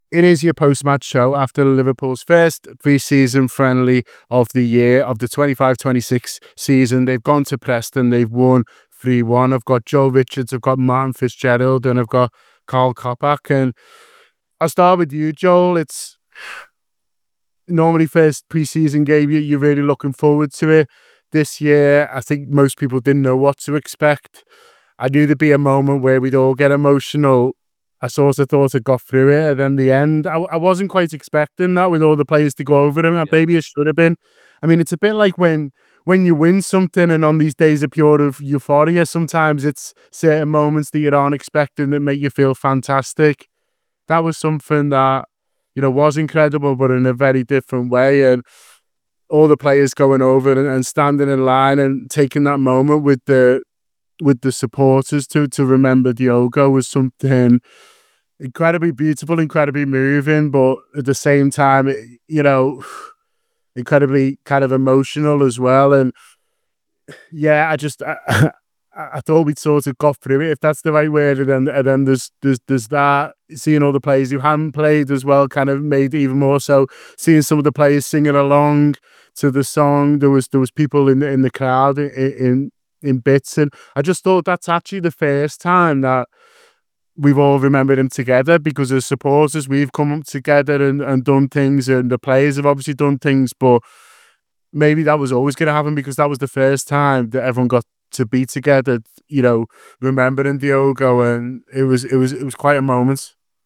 The Anfield Wrap’s post-match reaction podcast after Liverpool’s first pre-season game of the season, overcoming Preston North End by three goals to one.